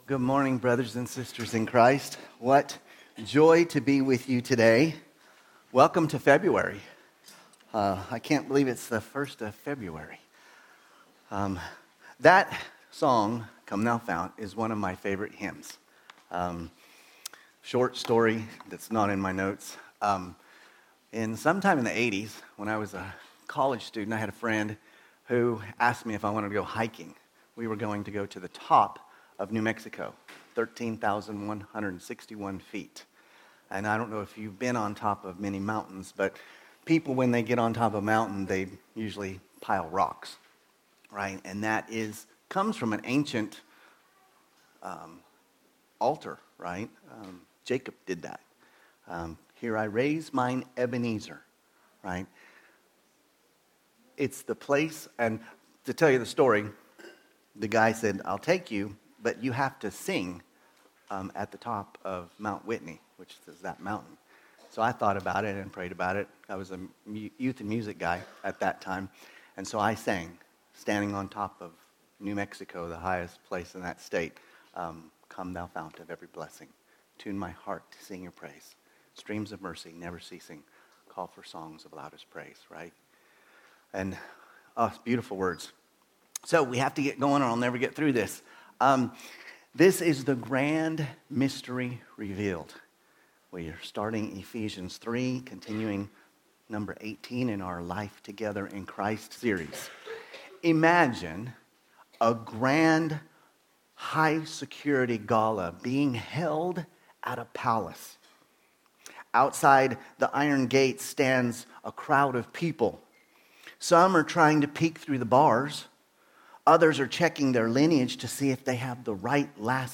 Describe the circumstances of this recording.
From Series: "Sunday Service"